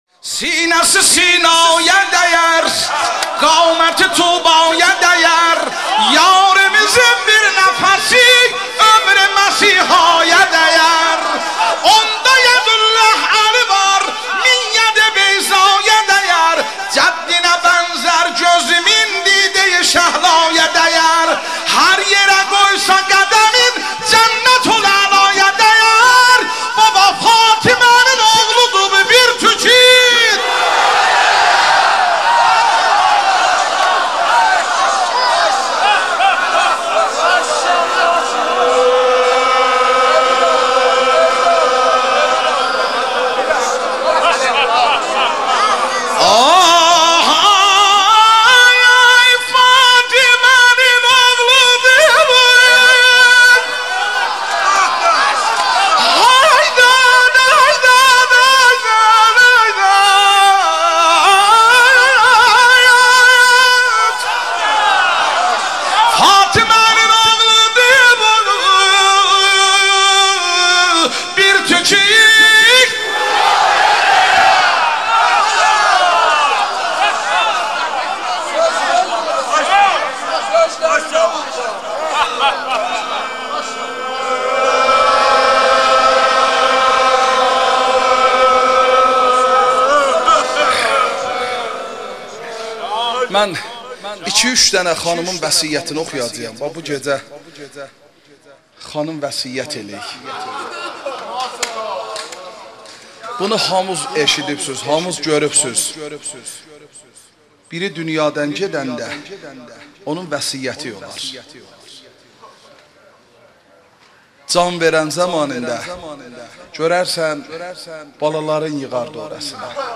شهادت حضرت زهرا ایام فاطمیه مداحی ترکی بخش اول